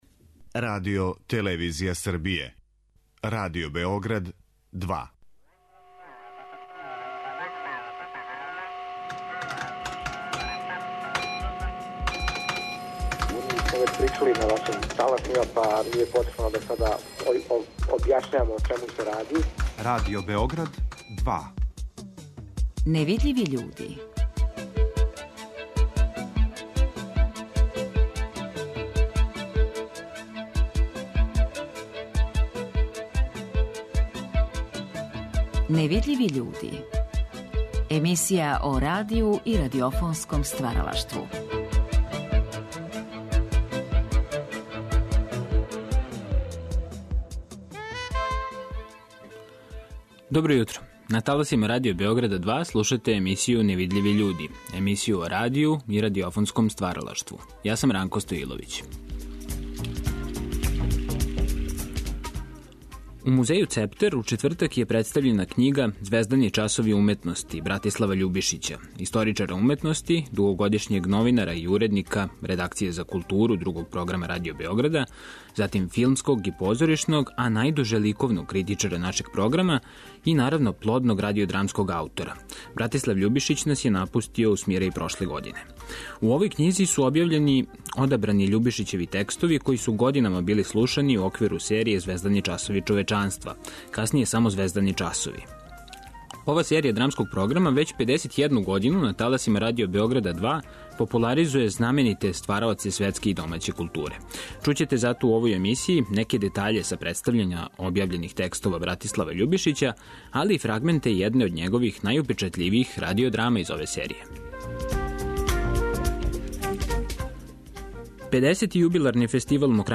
али и фрагменте неких од најупечатљивијих радио-драма из ове серије.